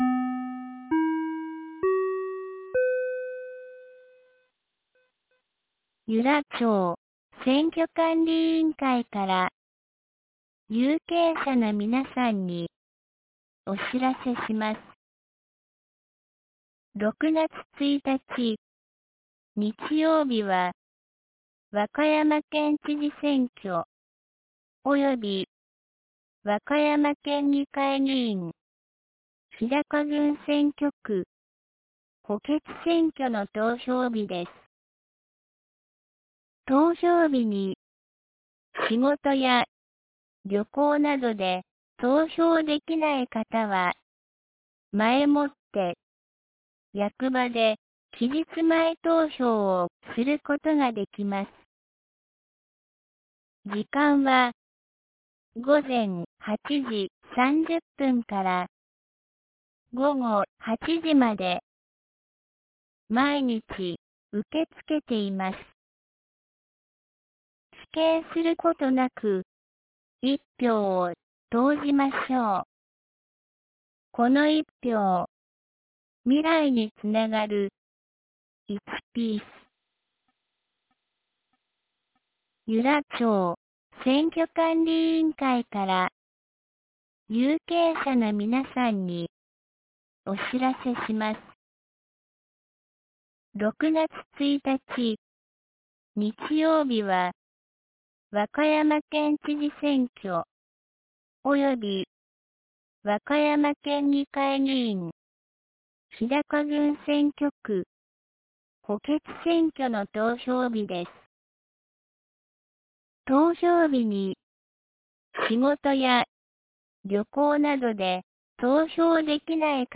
2025年05月26日 08時03分に、由良町から全地区へ放送がありました。